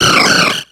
Cri de Jirachi dans Pokémon X et Y.